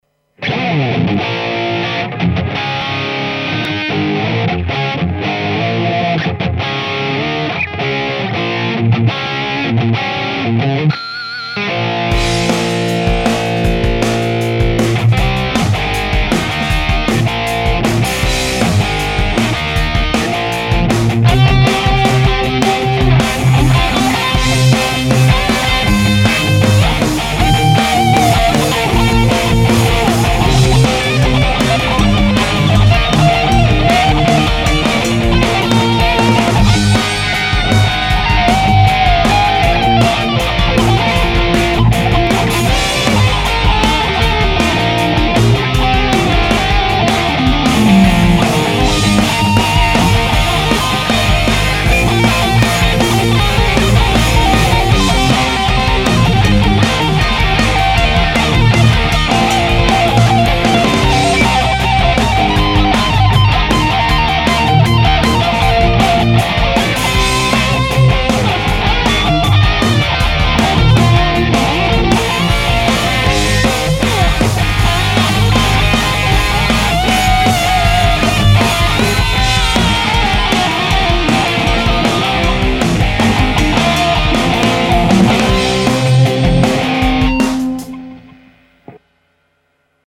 Pas mal de gens se demandent comment elle sonne alors .. Voilà quelques samples de la PRS !
C'est du brut de chez brut !
J'ai vraiment fait çà à l'arrache, mais çà donnera une idée sincère du côté "burné" de la PRS, je pense..